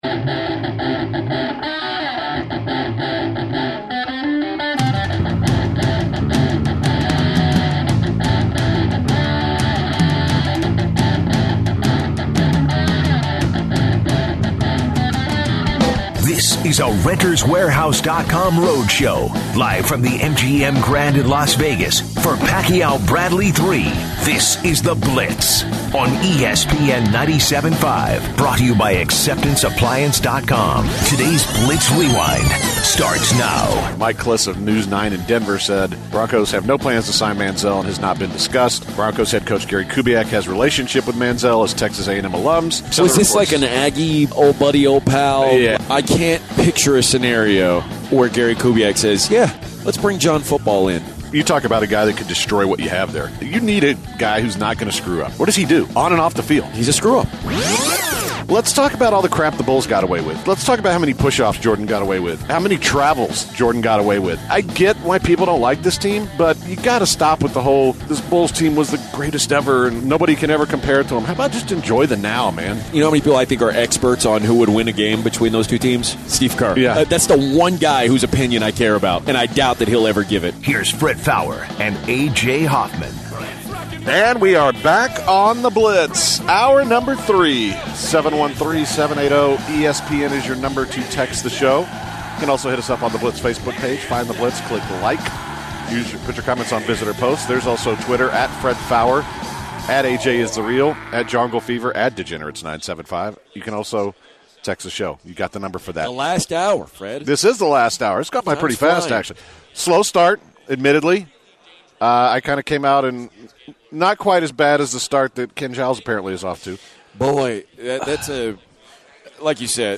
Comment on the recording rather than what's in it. Live from Las Vegas, NV. In this hour they discuss the Spurs strategy for their upcoming match-up with the Golden State Warriors. The releasing of the NFL preseason schedule, not getting married and poor hygiene are just some of the topics the guys hit during this hour.